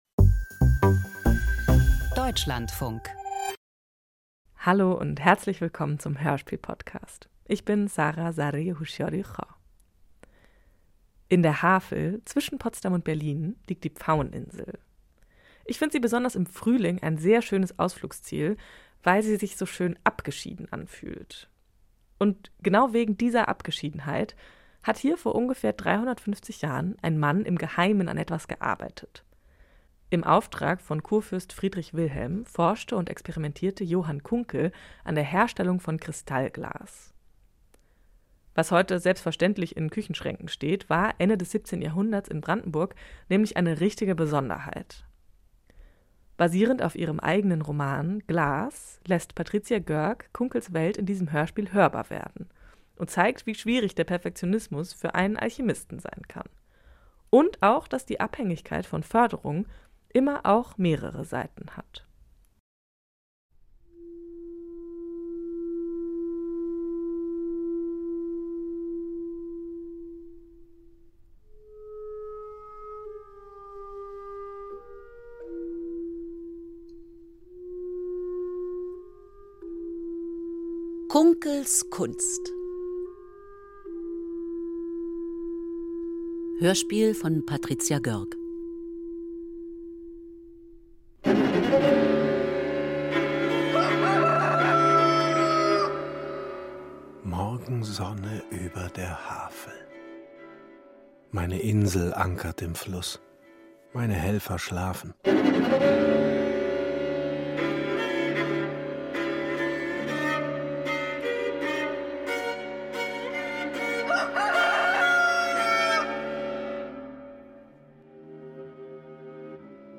Hörspiel: Alchemist im Preußen des 17. Jahrhunderts - Kunckels Kunst
• Literaturbearbeitung • Nicht Gold, aber Luxusglas soll er erschaffen – dafür schenkt der Große Kurfürst Friedrich Wilhelm seinem Alchemisten ein Geheimlabor auf der Berliner Pfaueninsel.